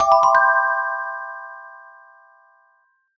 mention_received.ogg